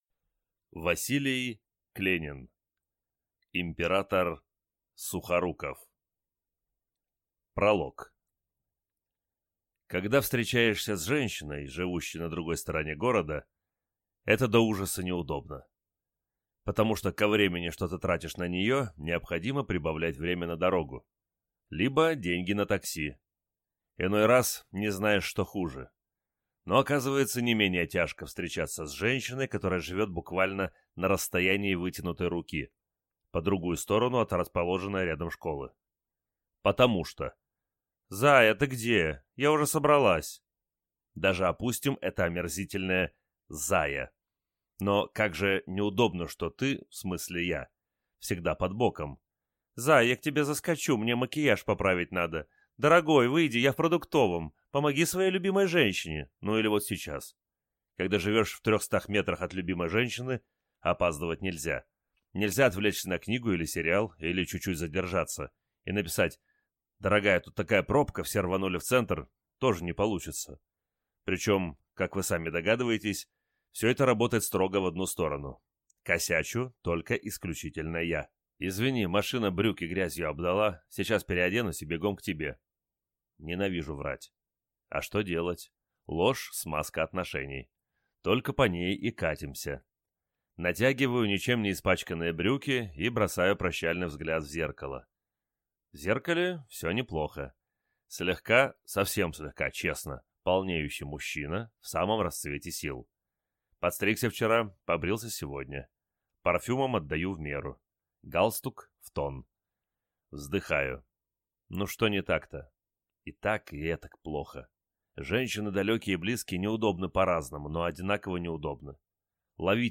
Аудиокнига Император Сухоруков | Библиотека аудиокниг